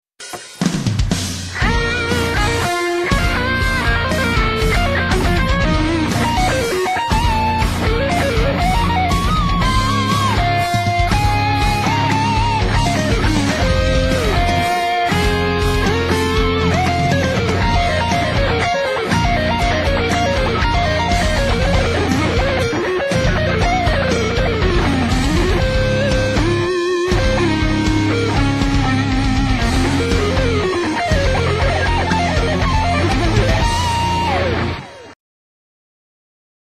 Rock Guitar
Solo